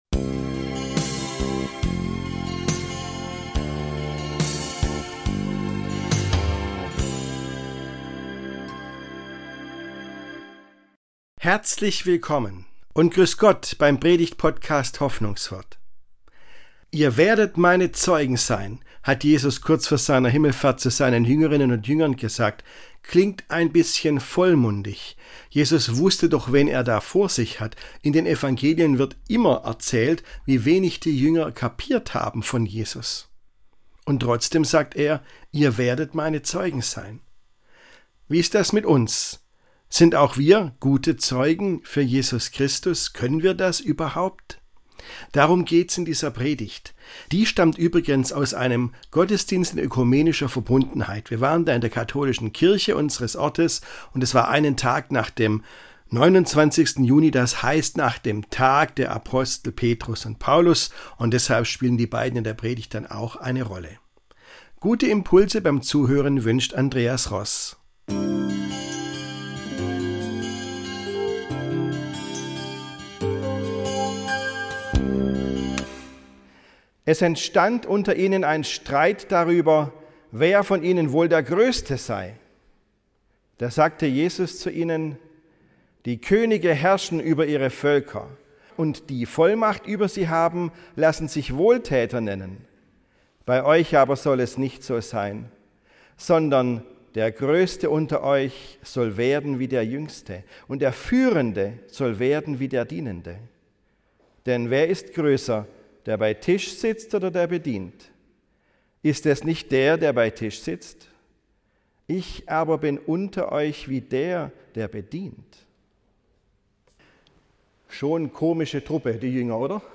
Zeugen sein für Jesus Christus ~ Hoffnungswort - Predigten